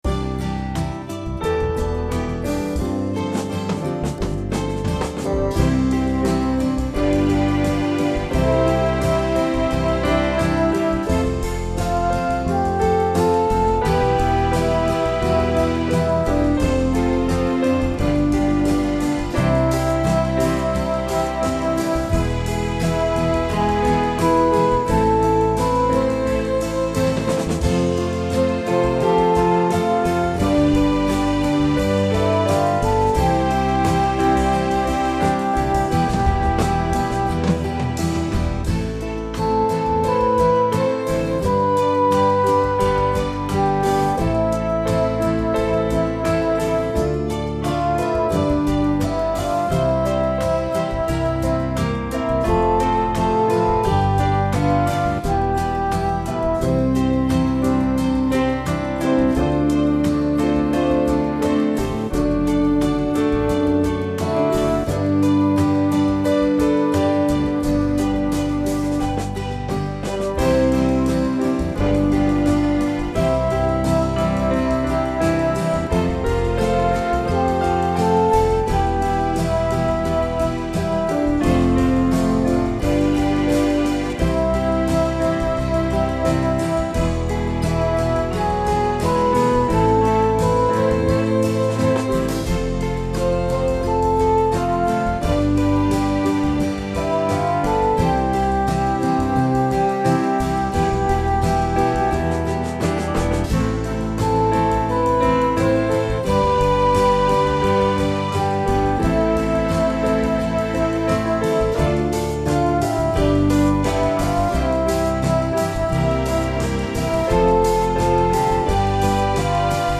My backing is at 87 bpm.